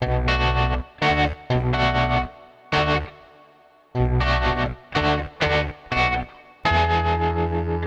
Bminguitarnbass.wav